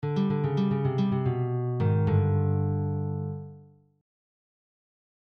Blues Exercises > Blues ending
Blues+ending.mp3